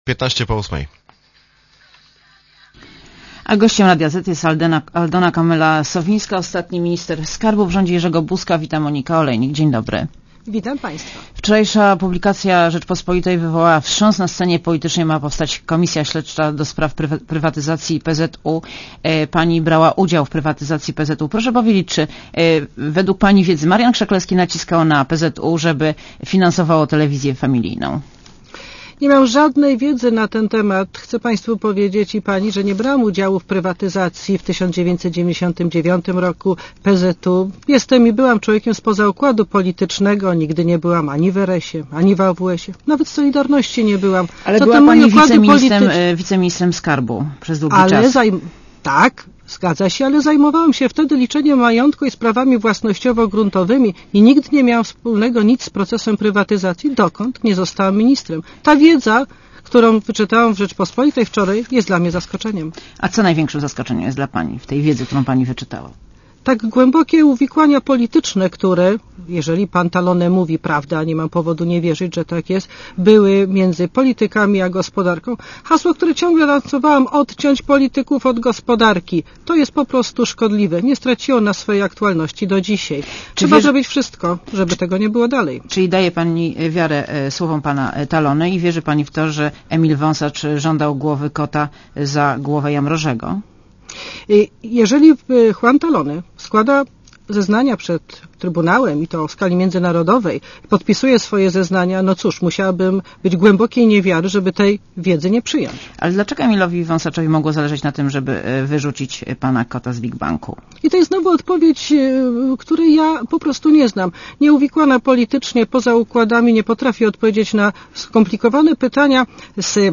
Gościem Radia Zet jest Aldona Kamela-Sowińska, ostatni Minister Skarbu w rządzie Jerzego Buzka.